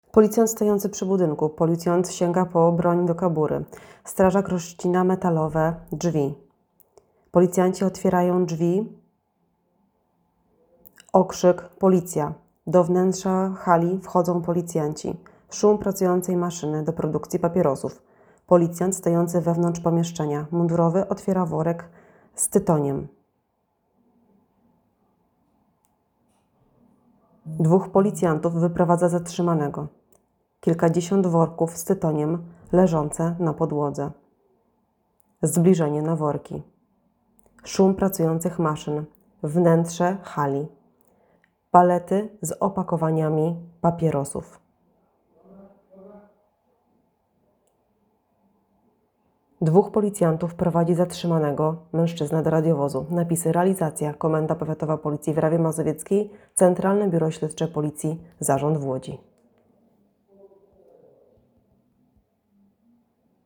Nagranie audio Audiodeskrypcja filmu